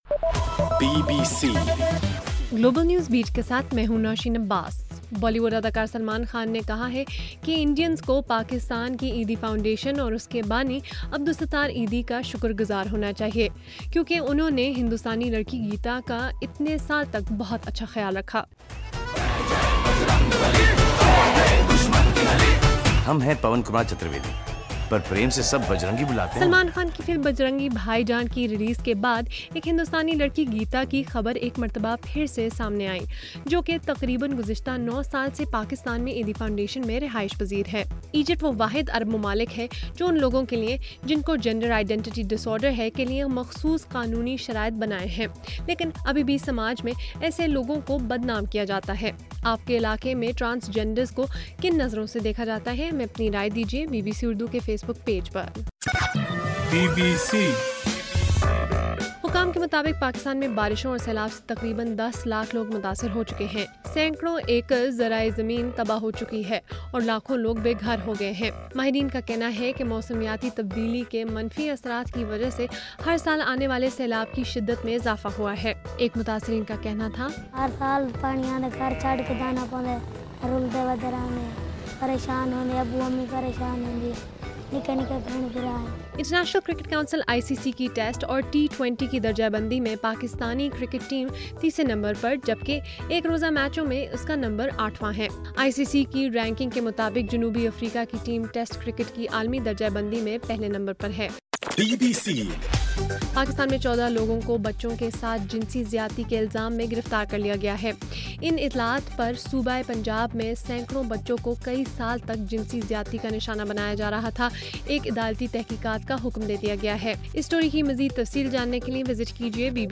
اگست 12: صبح 1 بجے کا گلوبل نیوز بیٹ بُلیٹن